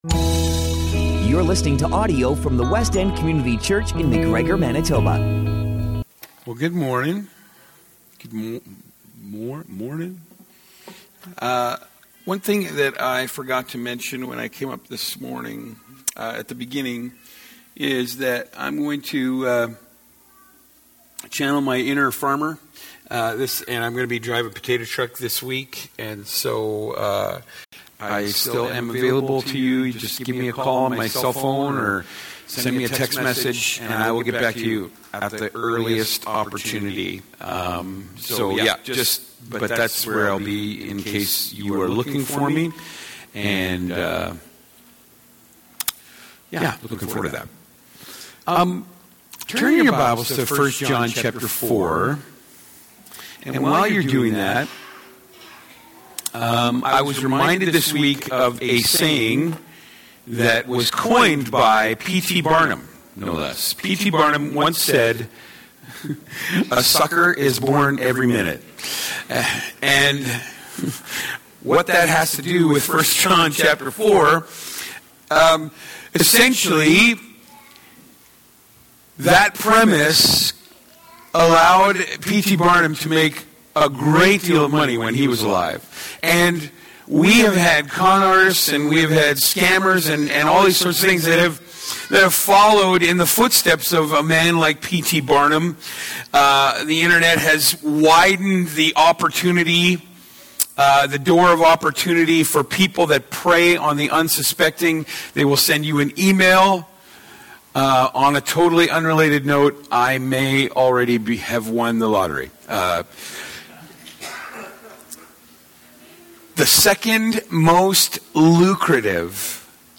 Sermons - Westend Community Church